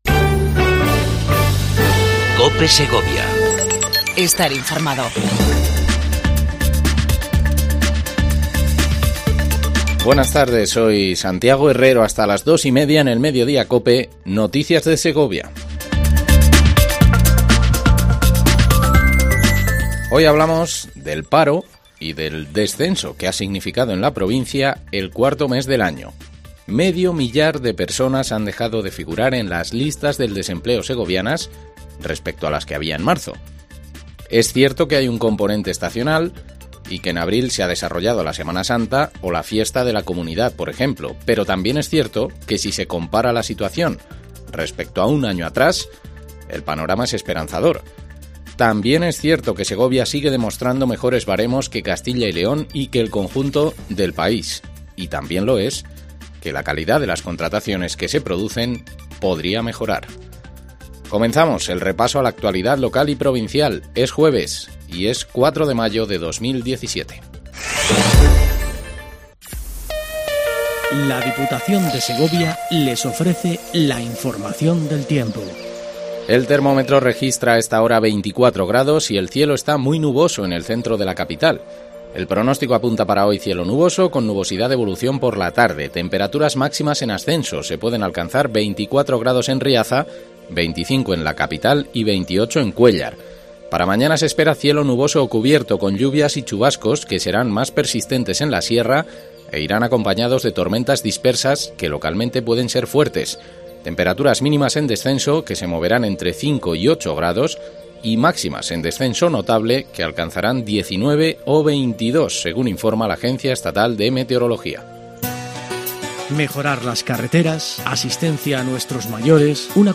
INFORMATIVO MEDIODIA COPE EN SEGOVIA 04 05 17